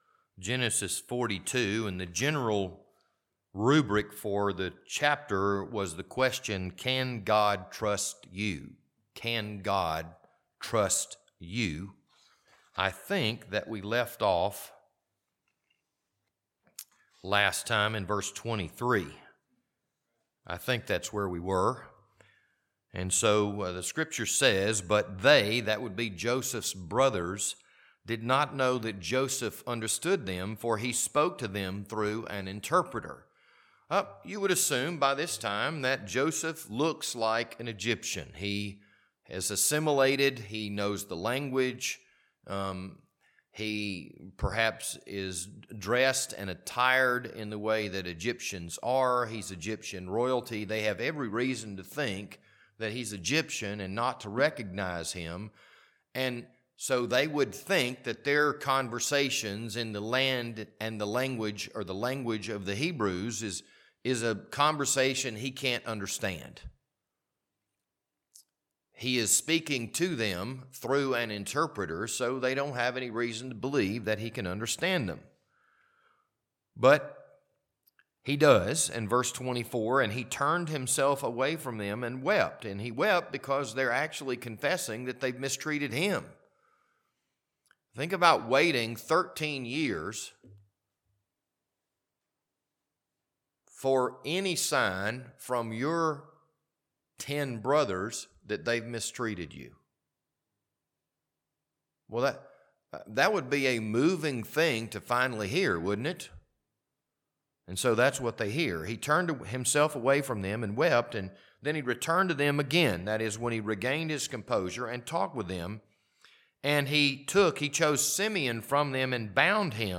This Wednesday evening Bible study was recorded on May 15th, 2024.